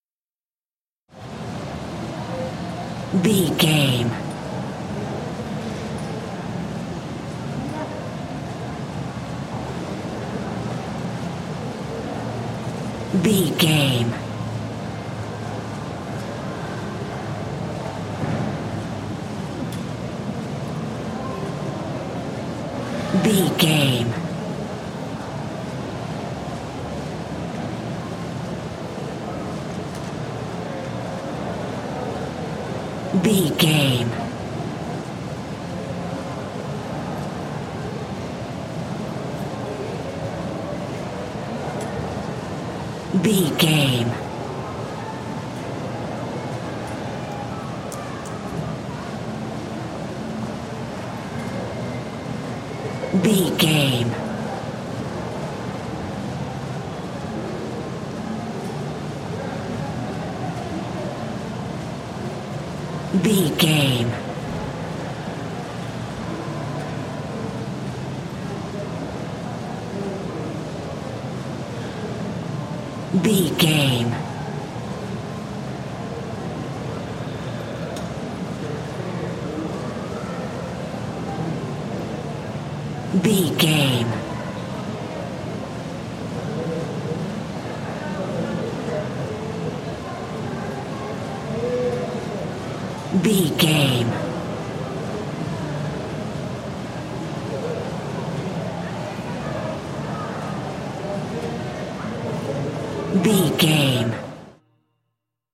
Mall ambience
Sound Effects
urban
ambience